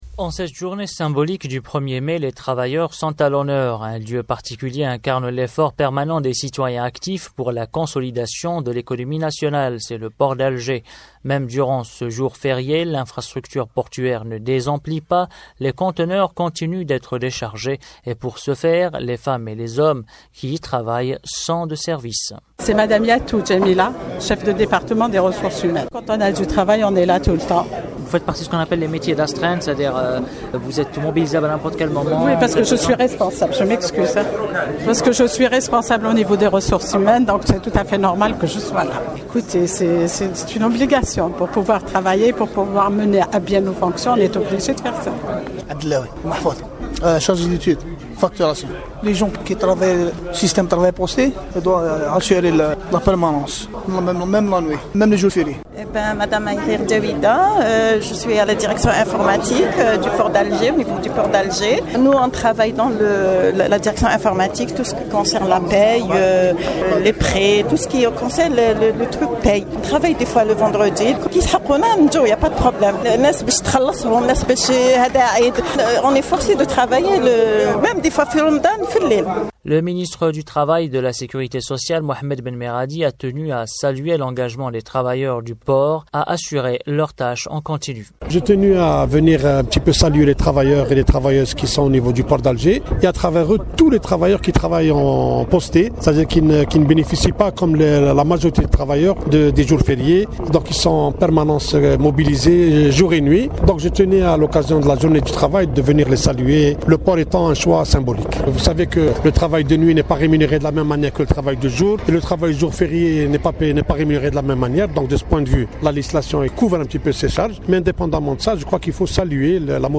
Compte rendu
Témoignages des travailleurs
à partir du port d'Alger